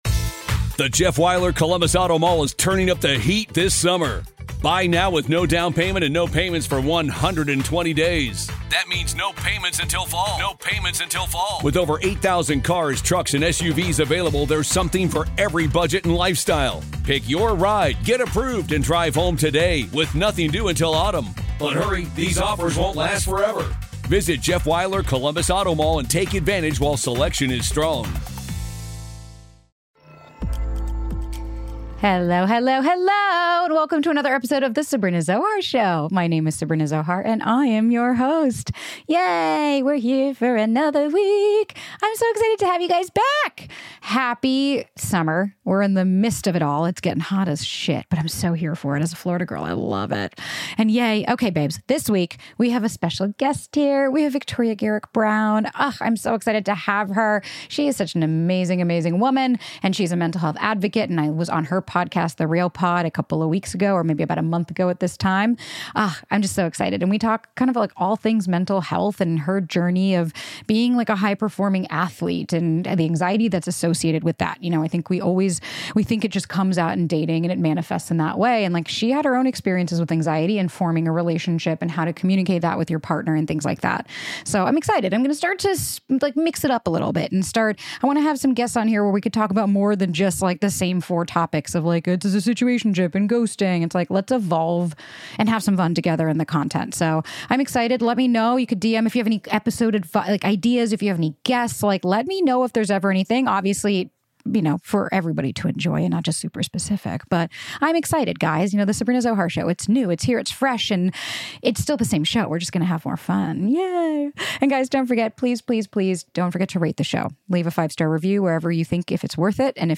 They discuss the importance of authenticity and the challenges of maintaining mental health, emphasizing that it is a continuous effort.